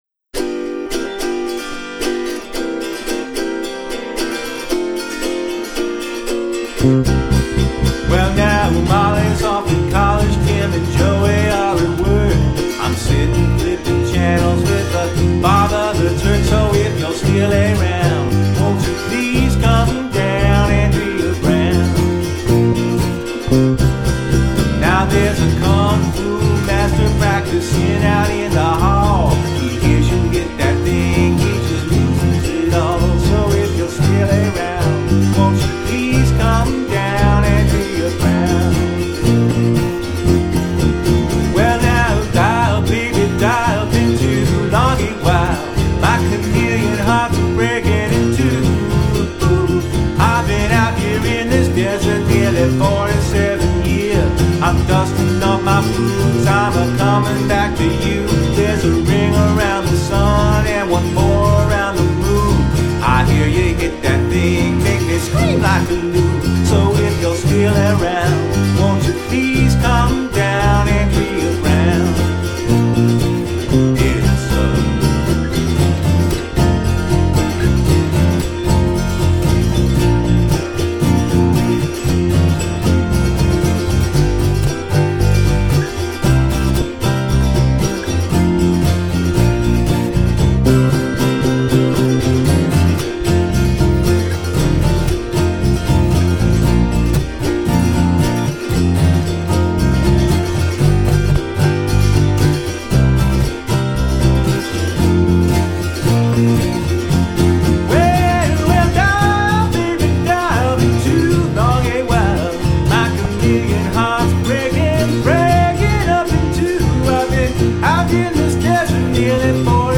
autoharps
and I was often playing guitar with a capo.
Andrea Brown is the more uptempo. It is still in need of a guitar solo that I didn’t provide at the time.